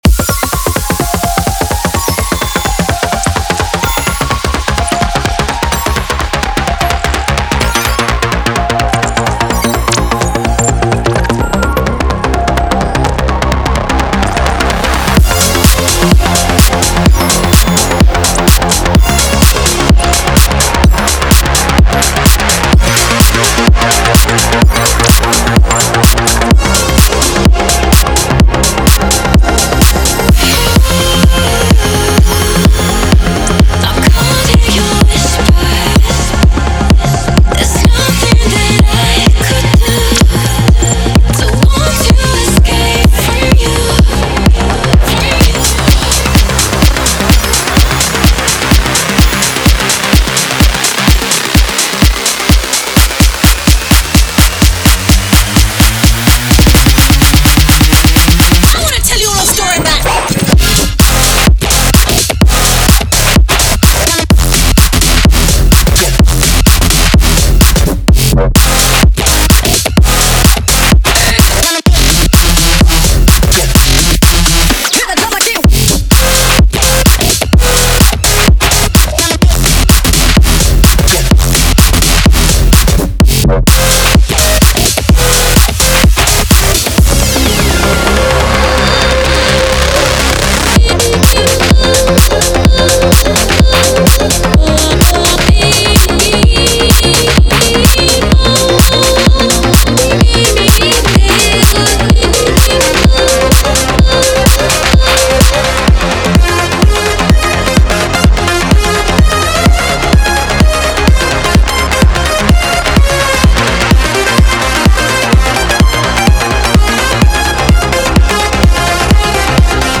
Future House, Energetic, Dark, Gloomy, Angry, Restless